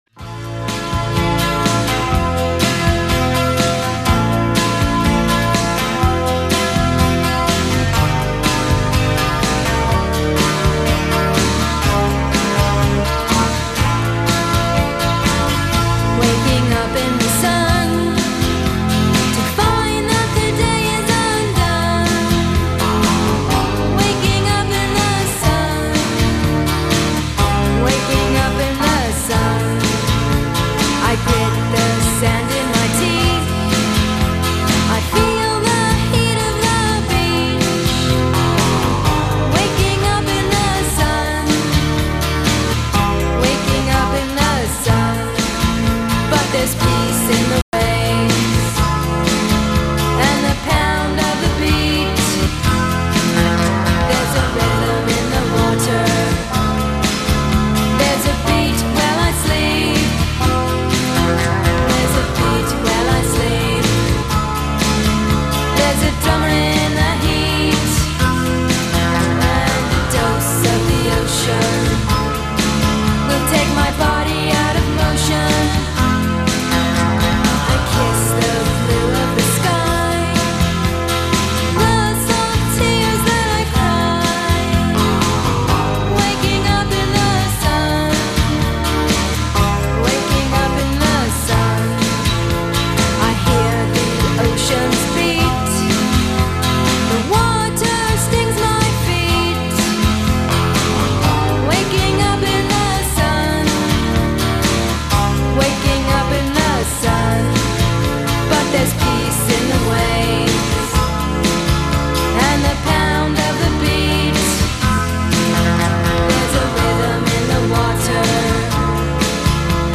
singer and guitarist